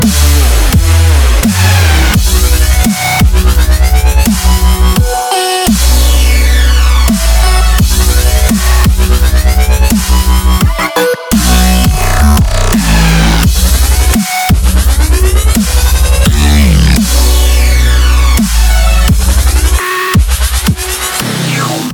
• Качество: 192, Stereo
Мощно, красиво, объемно!